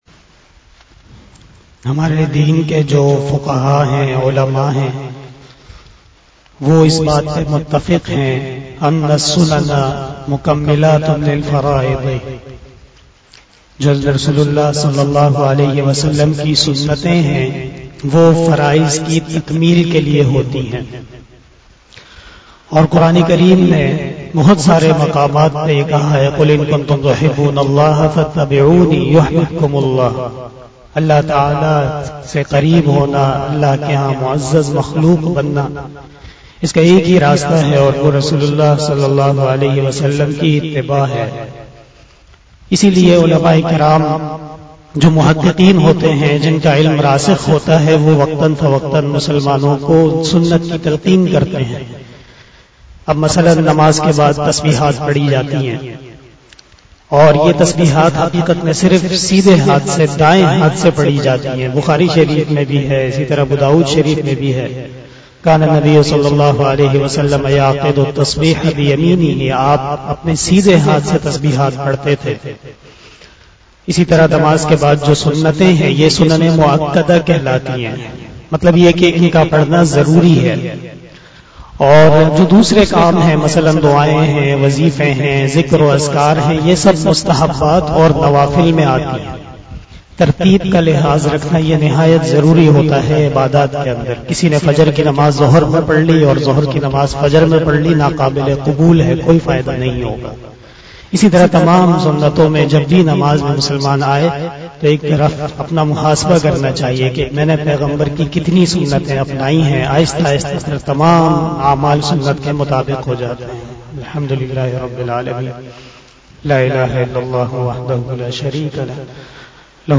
070 After Asar Namaz Bayan 07 October 2021 (29 Safar 1443HJ) Thursday